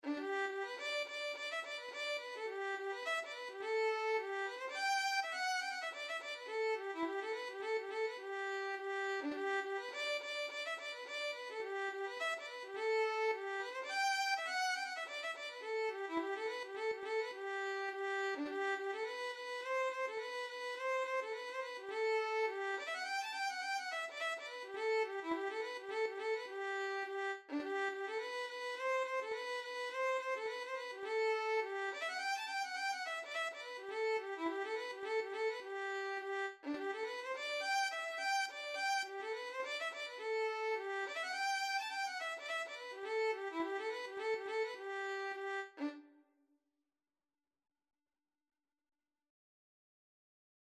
Traditional Music of unknown author.
4/4 (View more 4/4 Music)
D5-A6